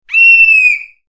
Spooky Halloween Sound Effects
scream-2-real.mp3